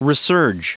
Prononciation du mot resurge en anglais (fichier audio)
resurge.wav